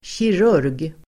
Ladda ner uttalet
kirurg substantiv, surgeon Uttal: [tjir'ur:g] Böjningar: kirurgen, kirurger Definition: operationsläkare operator substantiv, kirurg , operatör surgeon substantiv, kirurg Exempel: dental surgeon (tandläkare)
kirurg.mp3